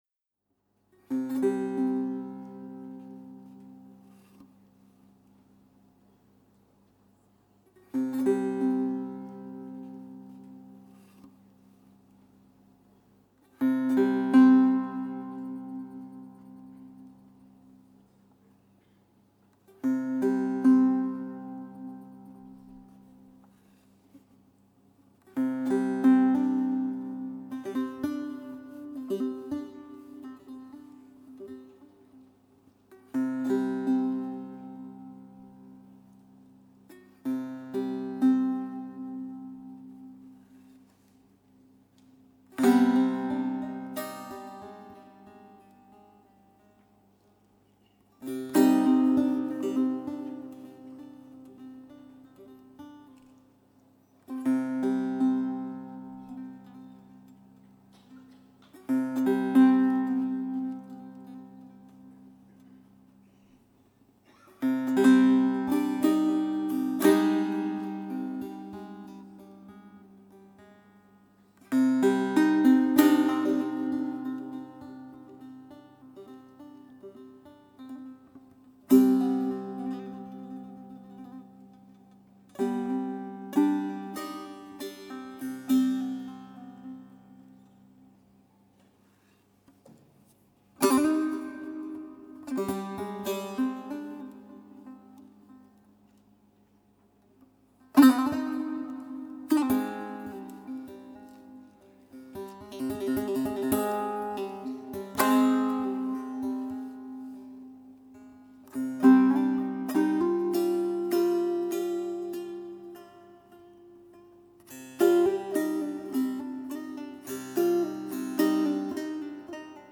Saz o Avaze Bedaheh